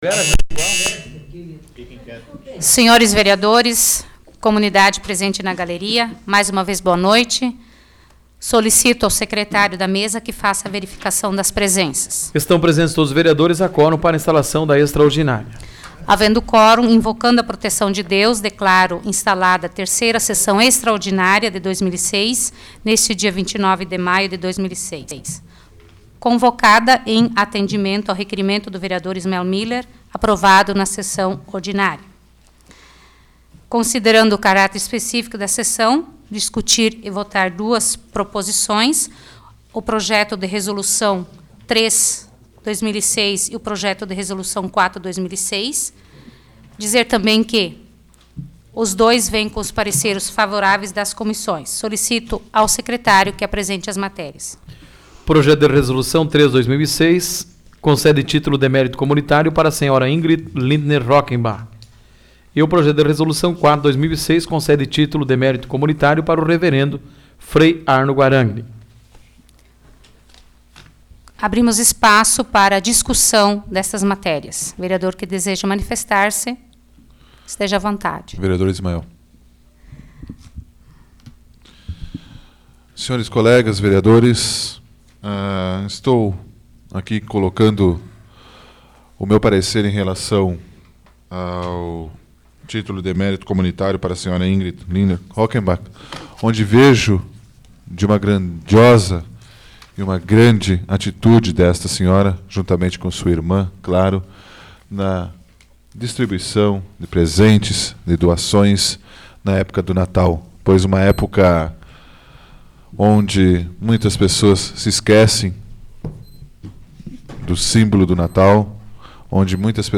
Áudio da 18ª Sessão Plenária Extraordinária da 12ª Legislatura, de 29 de maio de 2006